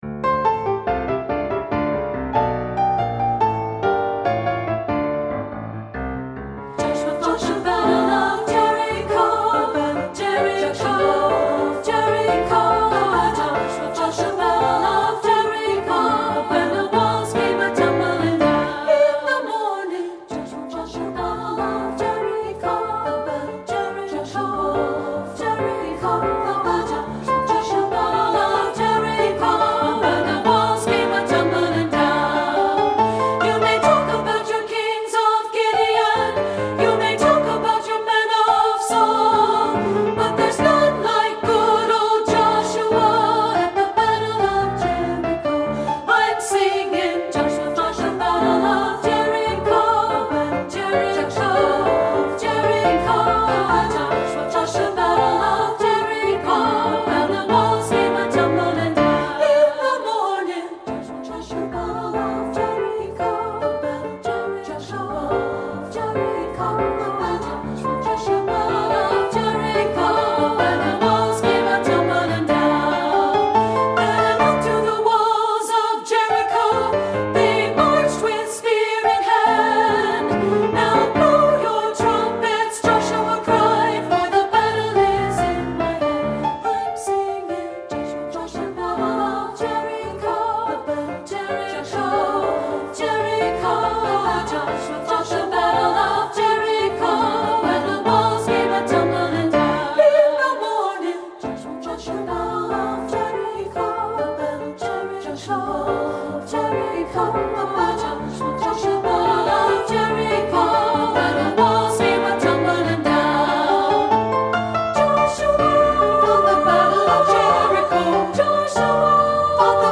Composer: African-American Spiritual
Voicing: 2-Part